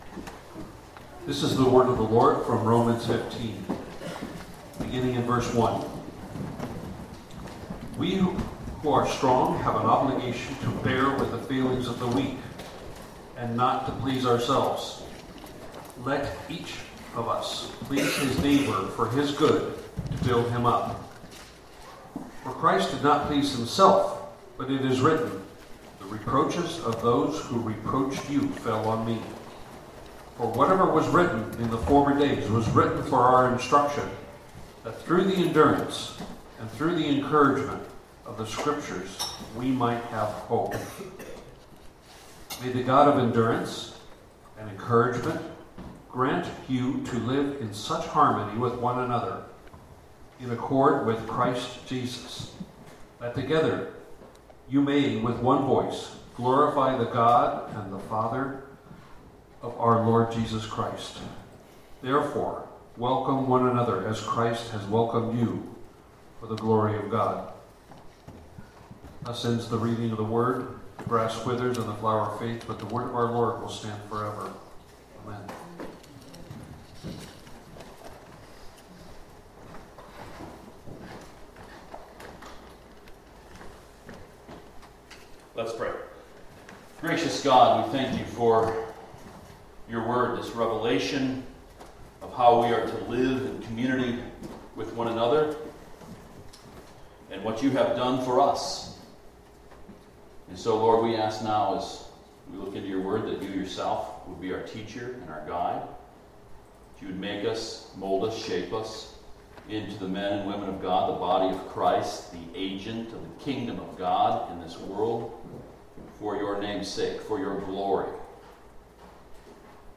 Passage: Romans 15 Service Type: Sunday Morning